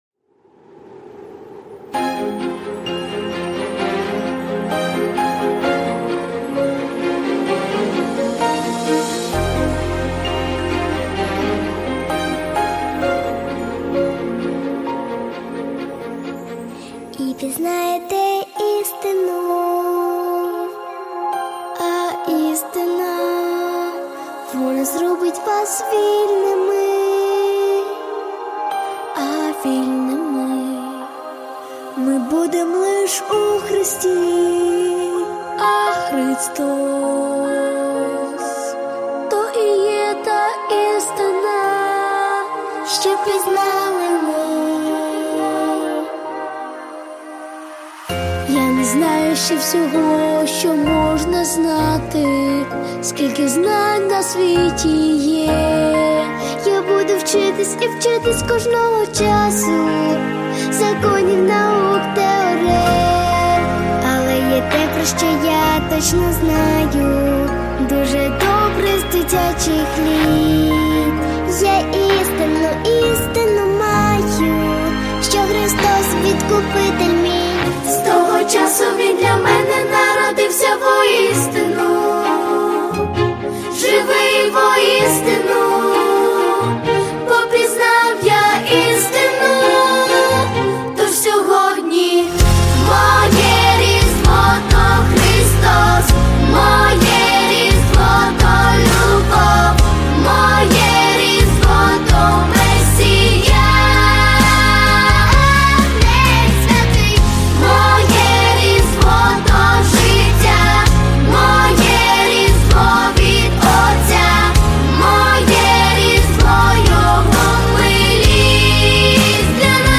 • Качество: Хорошее
• Жанр: Детские песни
христианские песни